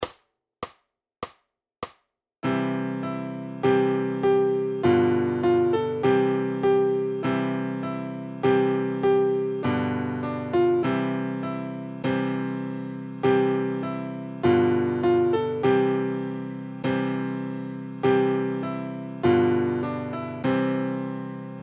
Noty na snadný klavír.
Aranžmá Noty na snadný klavír
Hudební žánr Vánoční písně, koledy